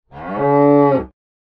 animalia_cow_death.ogg